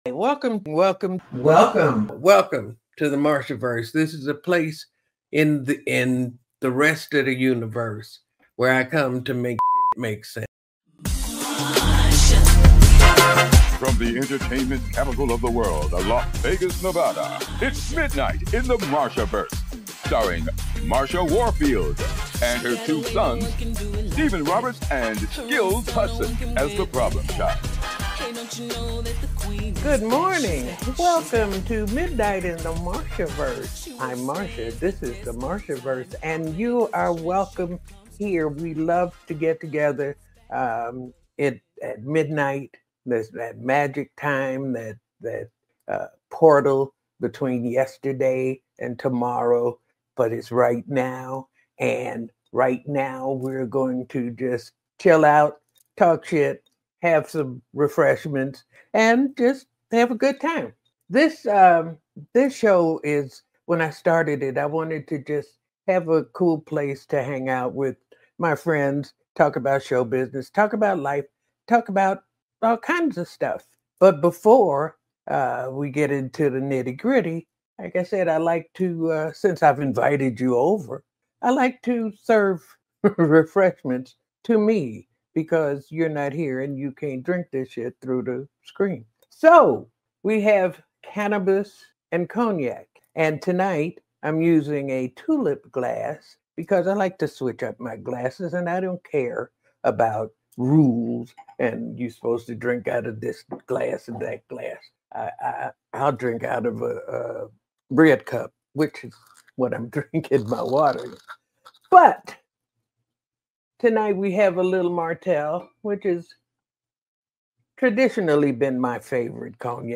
This week on Midnight in the Marshaverse, Marsha Warfield welcomes acclaimed stand-up comedian Tammy Pescatelli for an enlightening conversation on the healing power of comedy during life’s toughest seasons—especially in today’s challenging times. Together with Marsha’s “comedy” sons, they explore her unique “Comediosophy,” blending humor, wisdom, and resilience into life lessons.